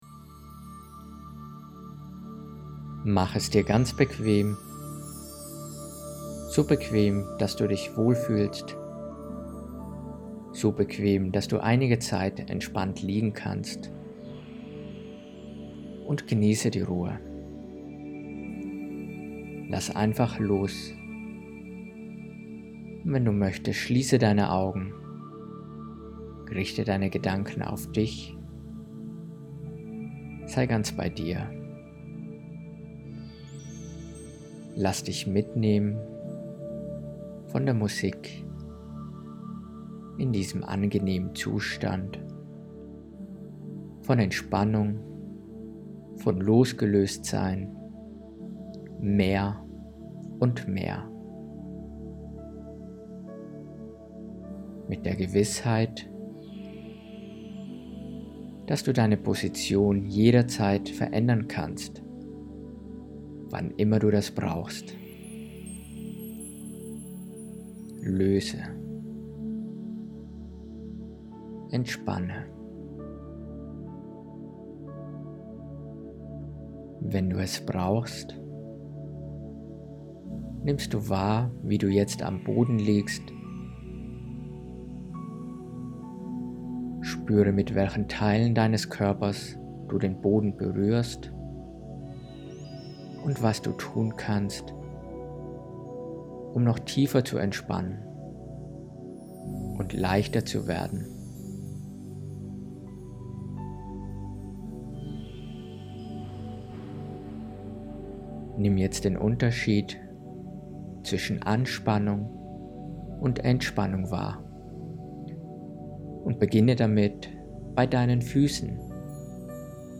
Gedankenreise durch den Körper – Eine Entspannungsübung
Ich mag Deine Gesundheitsinformationen, Deine Übungen , Deine angenehme Art dies zu vermitteln.
Entspannung-Gedankenreise.mp3